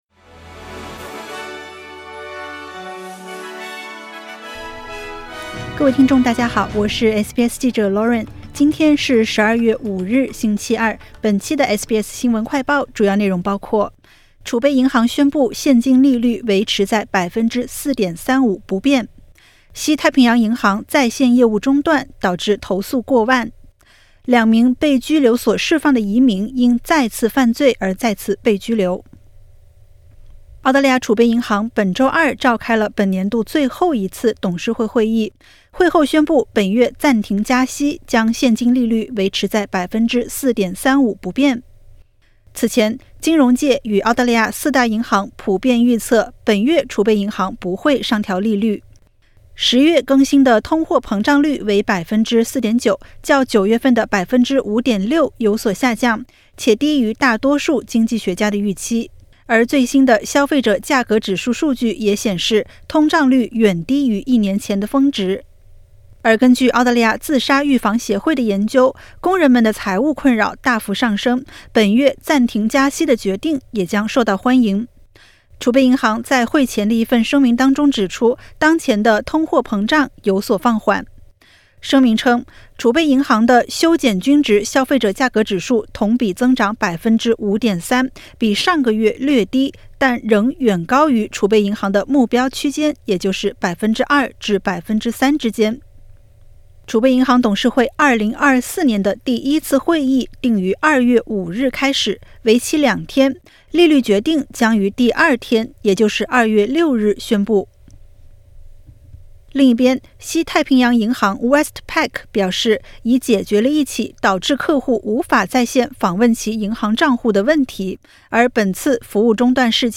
【SBS新闻快报】储备银行宣布现金利率维持在4.35%不变